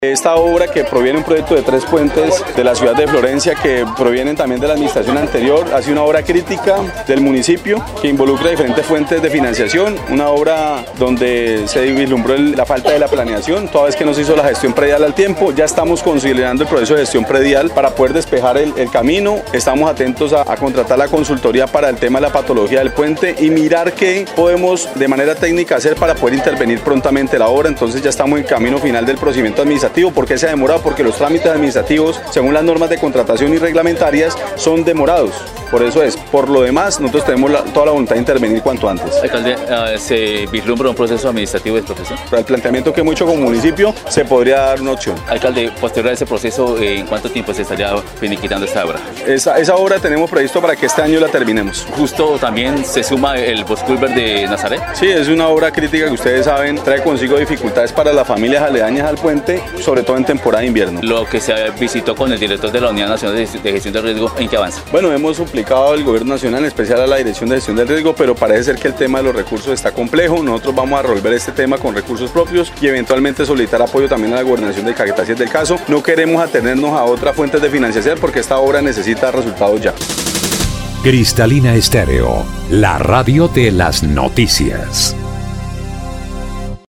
El alcalde de la ciudad agregó que, los trabajos en este punto de la ciudad deberán ser terminados antes de culminar el 2025.
02_ALCALDE_MONSALVE_ASCANIO_MATIKI.mp3